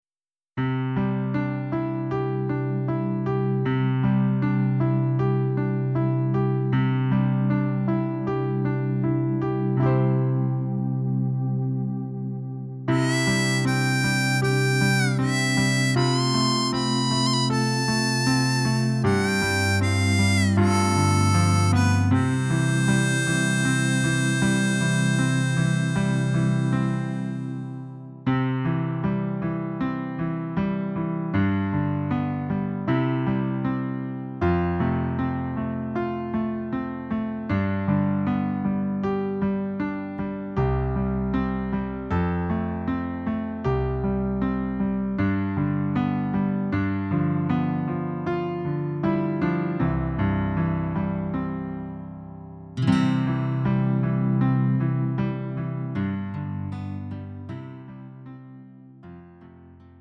음정 -1키 4:25
장르 가요 구분 Lite MR
Lite MR은 저렴한 가격에 간단한 연습이나 취미용으로 활용할 수 있는 가벼운 반주입니다.